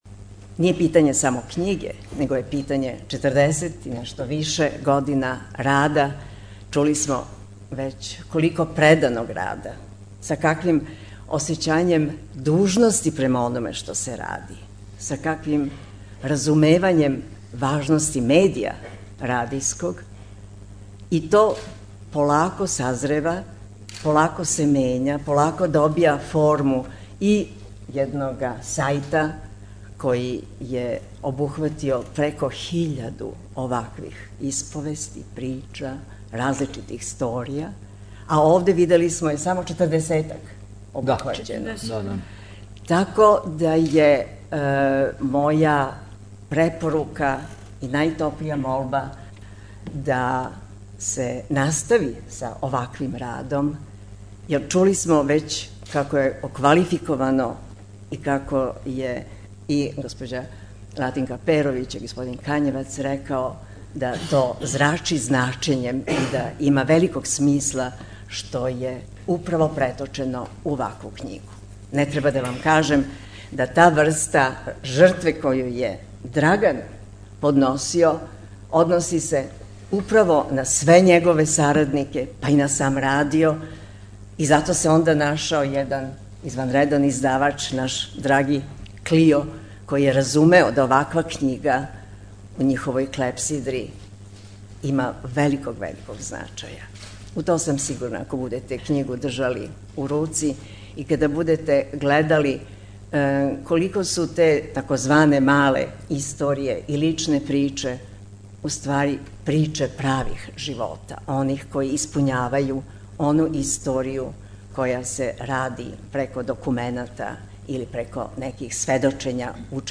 Razgovor o knjizi
pozivaju vas na predstavljanje knjige
Mala sala Kolarčeve zadužbine (Studentski trg 5, Beograd)